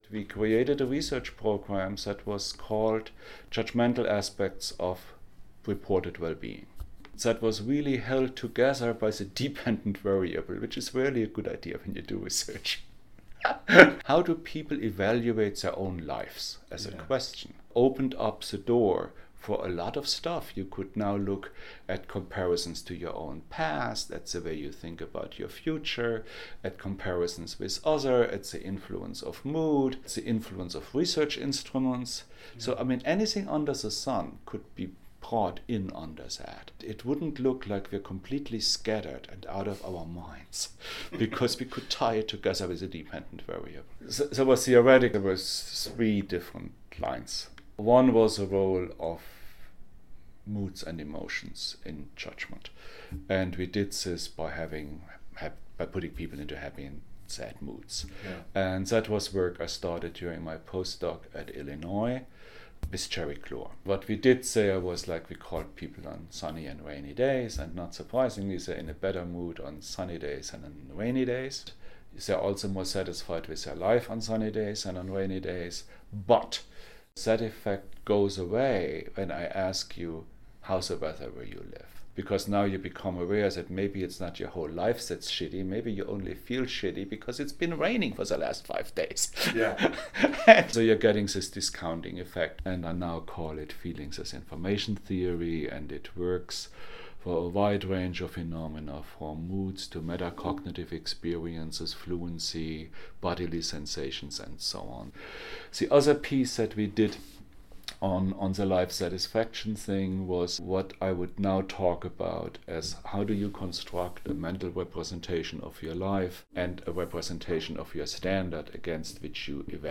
Here is one more excerpt from my conversation with Dr. Schwartz in which he recalled some of his major findings and theoretical constructs.